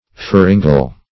pharyngal - definition of pharyngal - synonyms, pronunciation, spelling from Free Dictionary Search Result for " pharyngal" : The Collaborative International Dictionary of English v.0.48: Pharyngal \Pha*ryn"gal\, a. Pharyngeal.